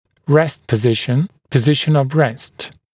[rest pə’zɪʃn] [pə’zɪʃn əv rest][рэст пэ’зишн] [пэ’зишн ов рэст]положение в состоянии покоя